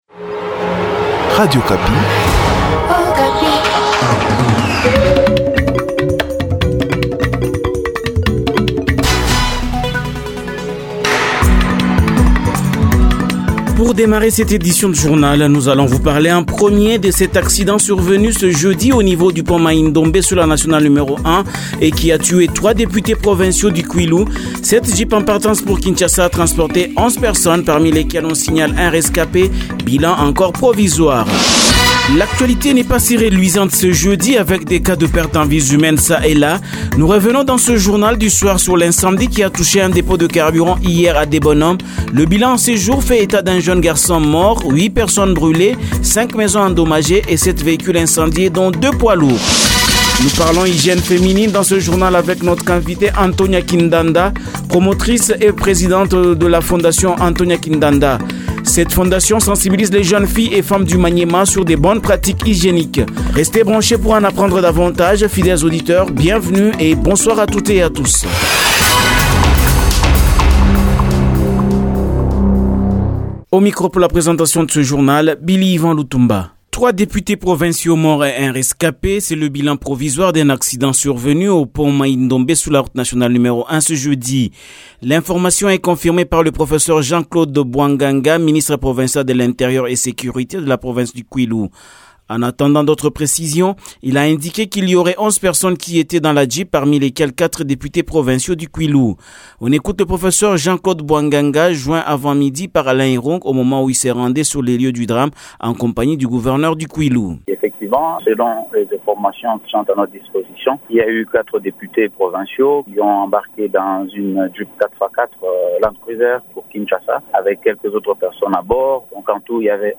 Journal Soir
Journal 18h de ce jeudi 17 mars 2022